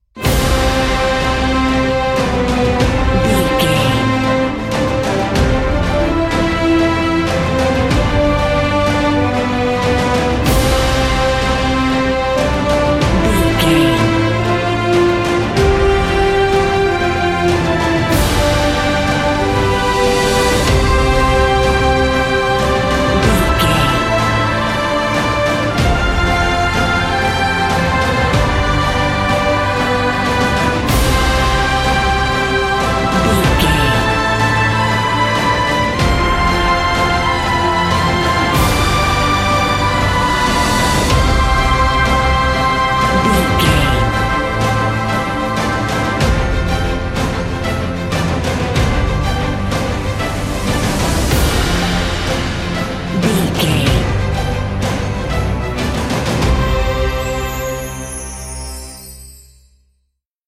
Uplifting
Ionian/Major
brass
drums
piano
strings